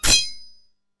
Sword Clash
Two steel swords clashing with bright metallic ring, scraping edge, and combat energy
sword-clash.mp3